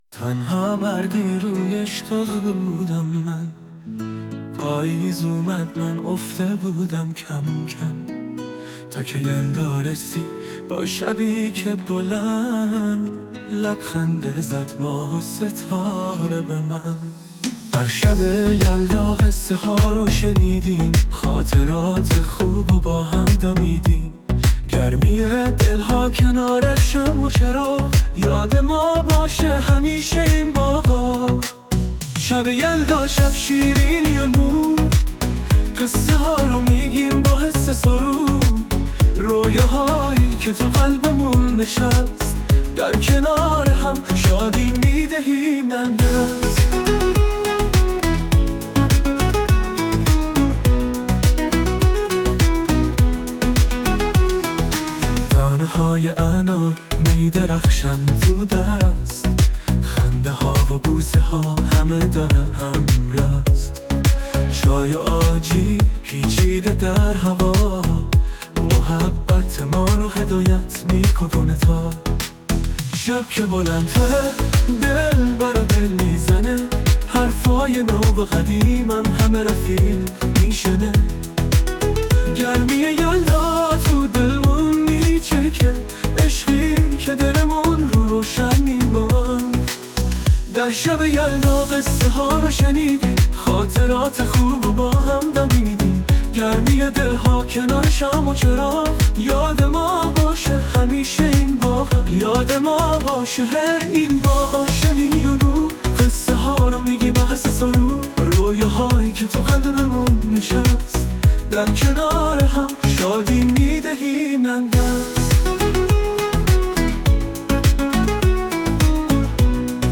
ساعدنیوز: دانلود شعر و آهنگ مخصوص شب یلدا شاد با هوش مصنوعی بصورت ویدئویی و صوتی با لینک مستقیم در ادامه با شما هستیم.
برچسب: شب یلدا شعر ادبی آهنگ شاد